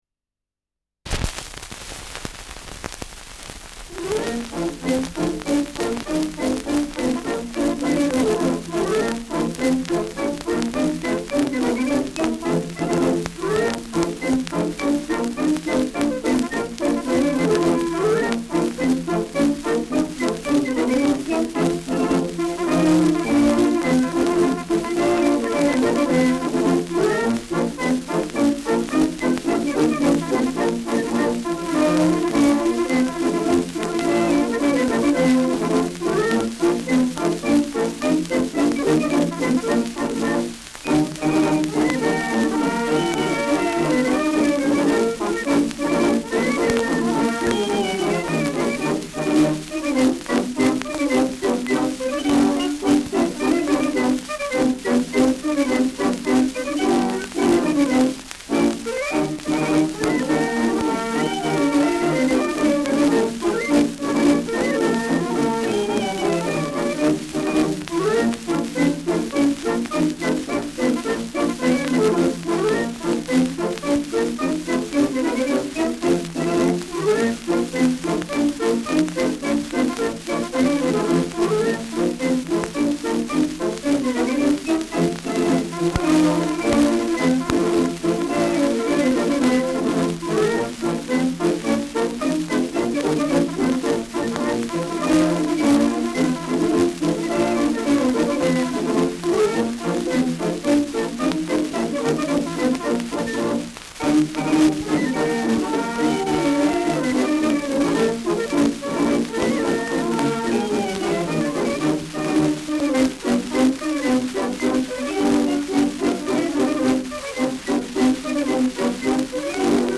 Schellackplatte
präsentes Rauschen : leichtes Knacken
Kapelle Peuppus, München (Interpretation)